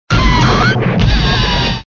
Cri de Heatran dans Pokémon Diamant et Perle.